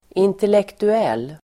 Uttal: [intelektu'el:]